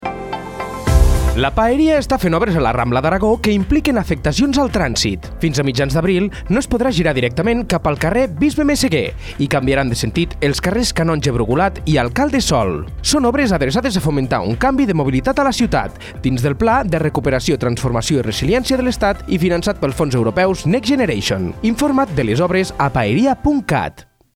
Primera falca radiofònica: